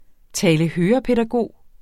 Udtale [ ˌtæːləˈhøːʌpεdaˌgoˀ ]